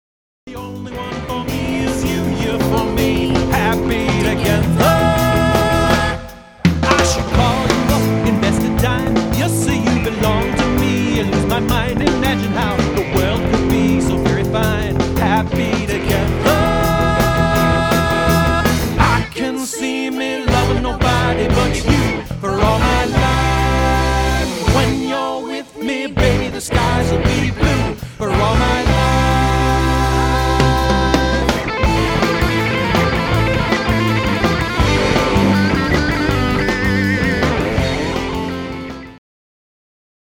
rockin version